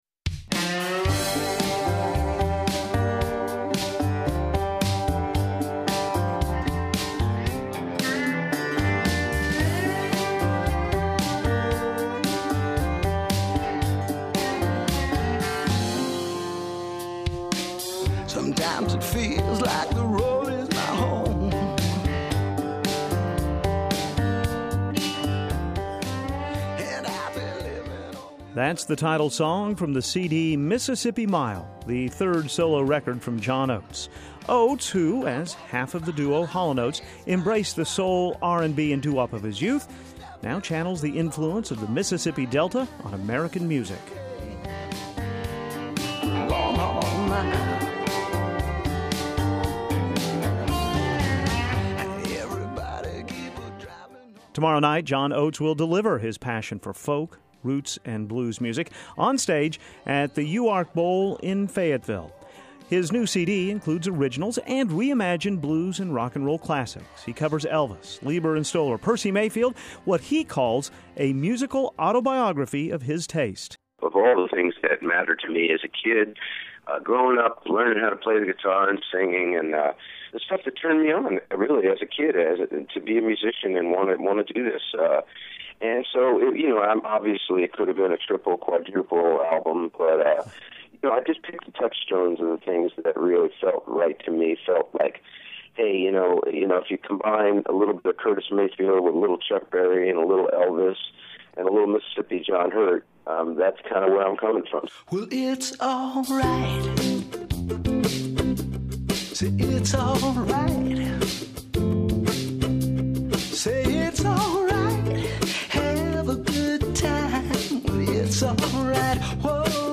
A Conversation with John Oates